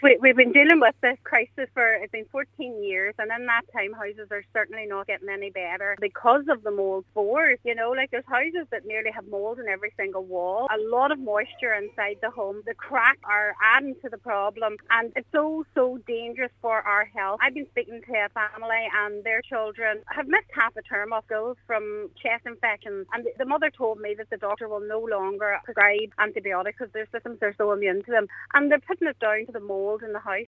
Speaking to Highland Radio, she revealed to what extent the issue is affecting people’s health: